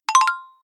new_message-CQKc7SF-.ogg